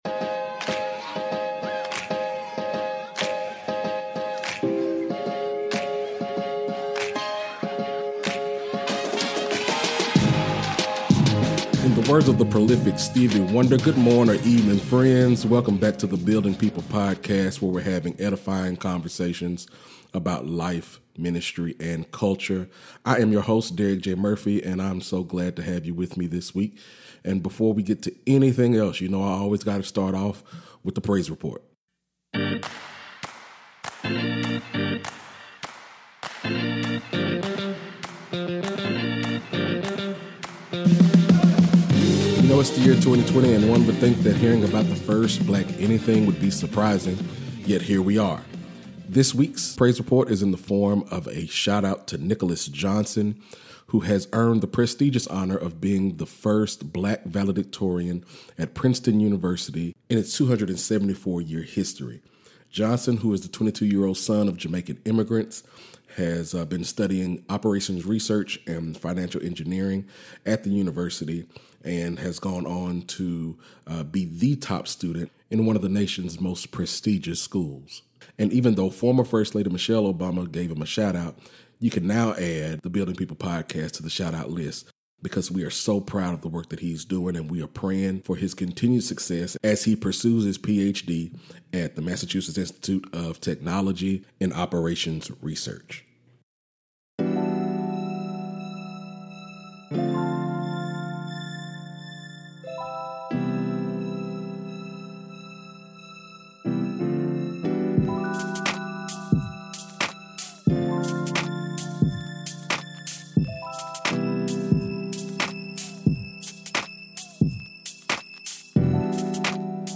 This episode features a surprisingly powerful conversation about the ways we lose ourselves for the sake of others